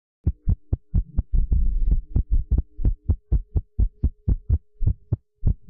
Irregular Fast Heartbeat